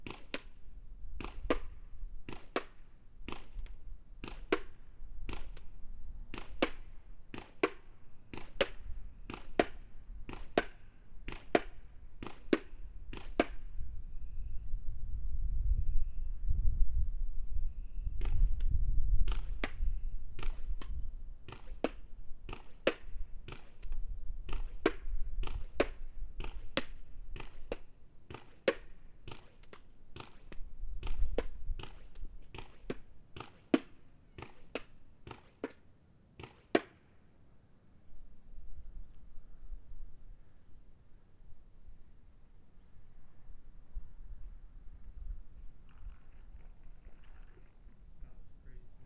paintball_splats_met..>2009-02-22 20:01 4.5M